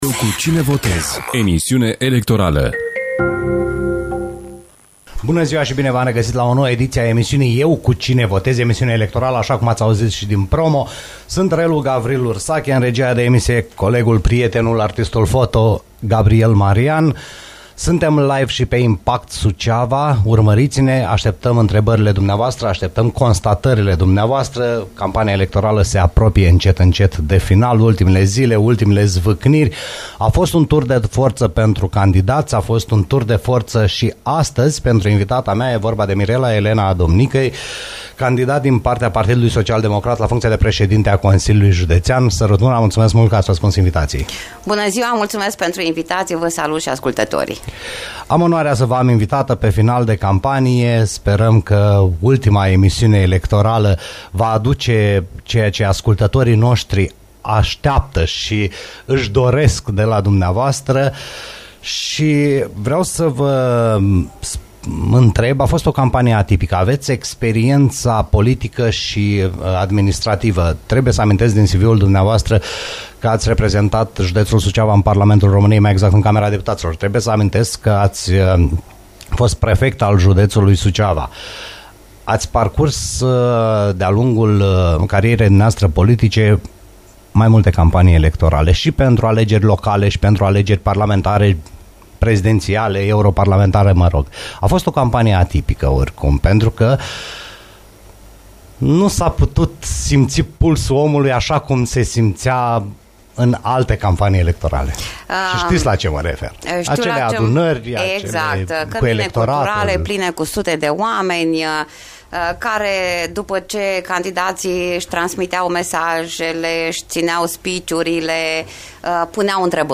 Mirela Adomnicăi, candidatul PSD la președinția Consiliului Județean Suceava, a fost invitata